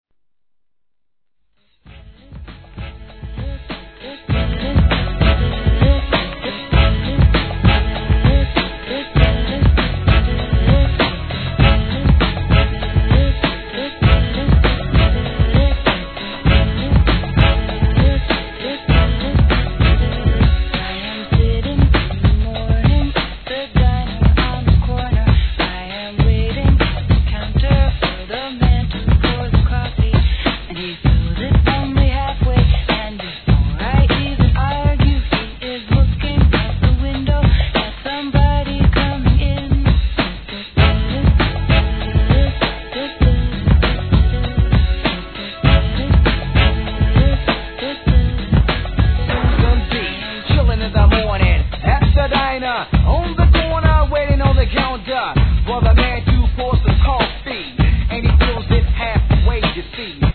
HIP HOP/R&B
当時人気のREMIXシリーズで、この盤オンリーMIX!!!!